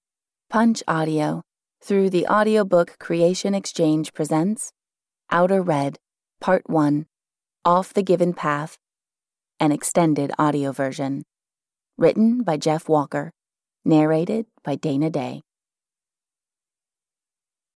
Outer Red: Part One (EN) audiokniha
Ukázka z knihy